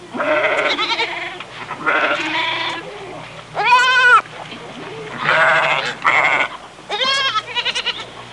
Barnyard Sound Effect
barnyard.mp3